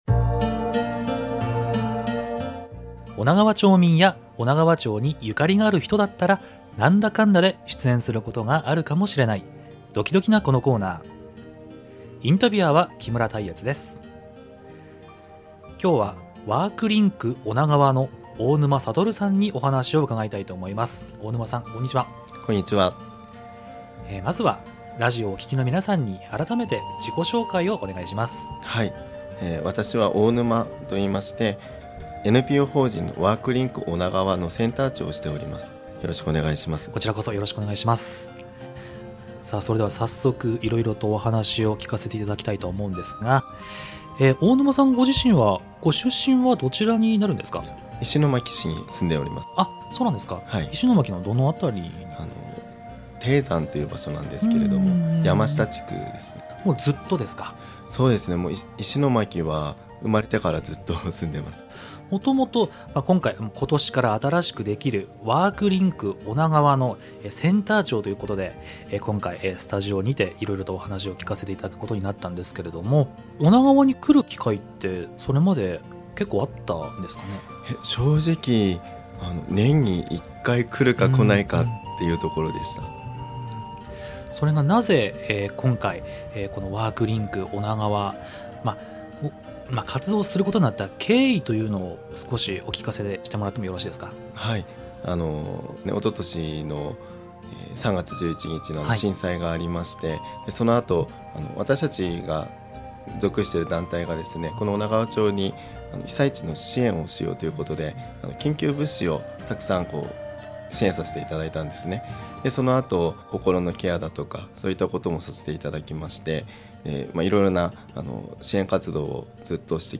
平日のおながわ☆なう。で放送している町民インタビュー番組 「この人さ聞いてみっちゃ」
本編ではその方のリクエストしていただいた音楽もお届けしていますが、 ポッドキャスト版では権利の都合でトーク部分のみとなります。